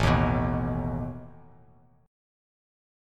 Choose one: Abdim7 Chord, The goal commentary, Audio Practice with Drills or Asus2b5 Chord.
Asus2b5 Chord